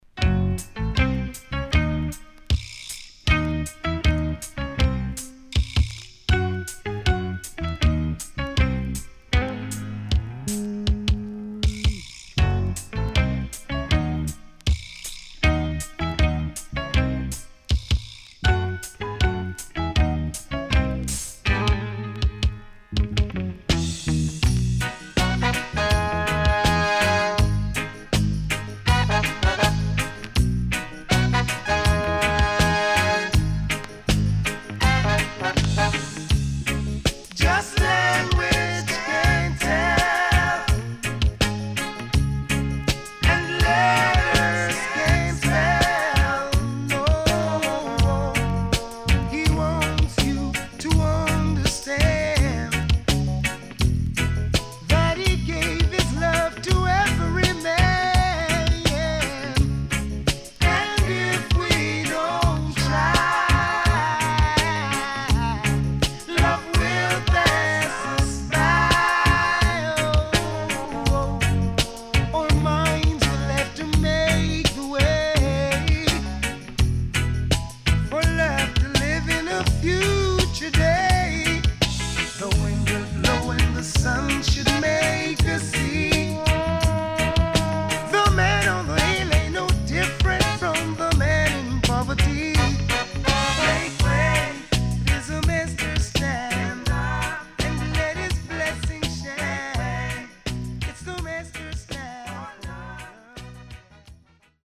SIDE B:少しチリノイズ入ります。